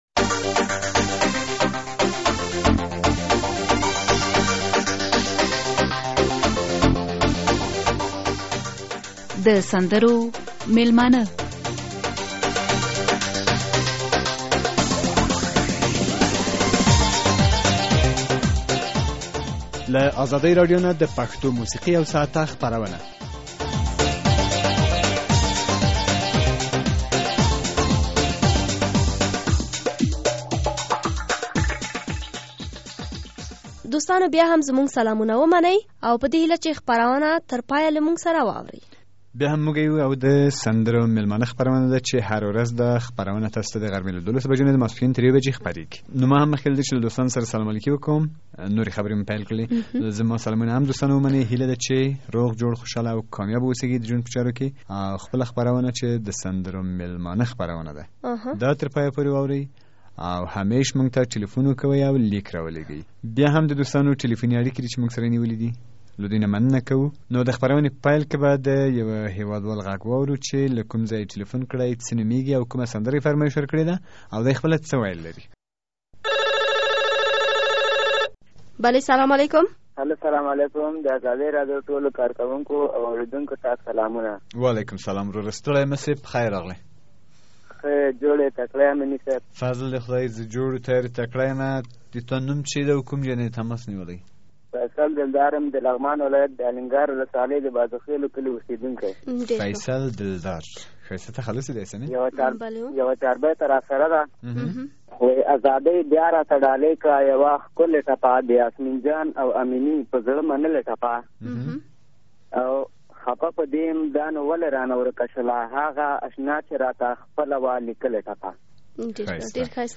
د يكشنبې د ورځې سندريز پروګرام واورئ